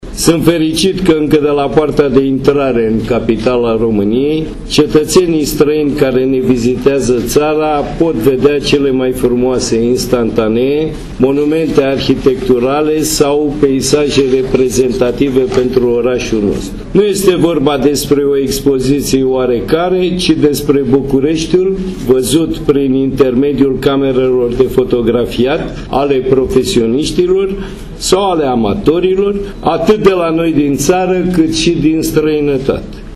Oprescu-vernisa-expozitie-Aeroport.mp3